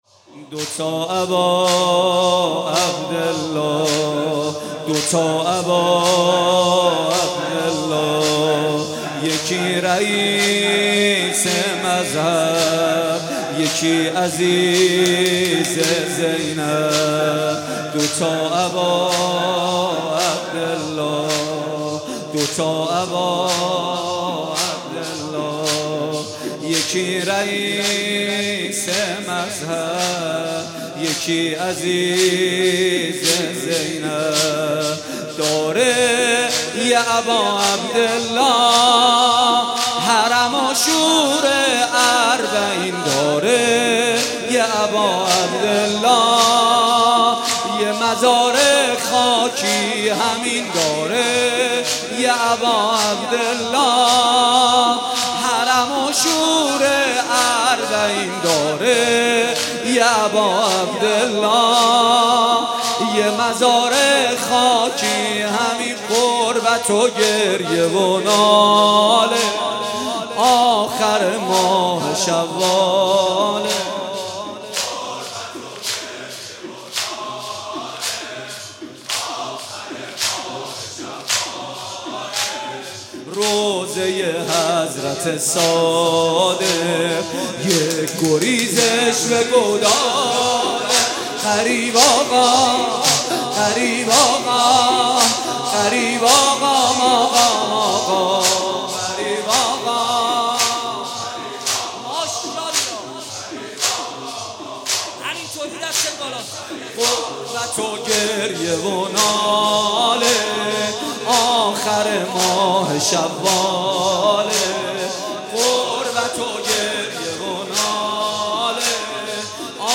در حال تکمیل/ صوت | مداحی امام صادق